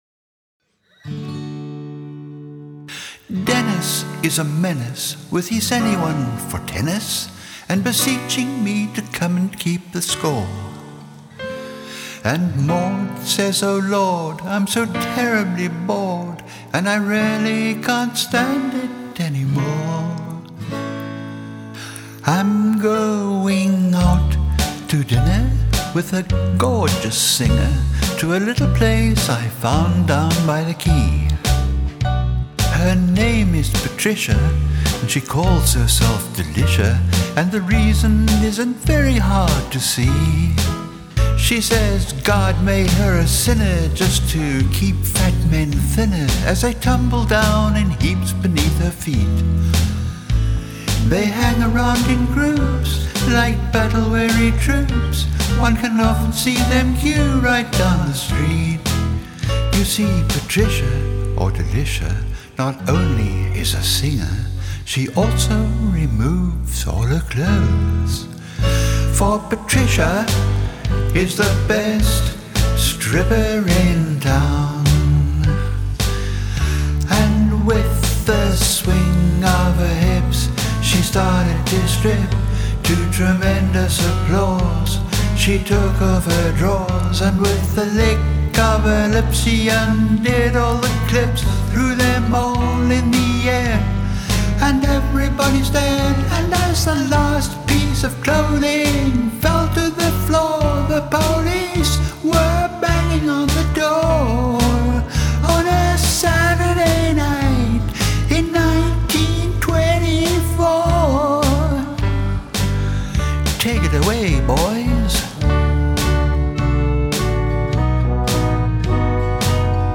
A rollicking old favourite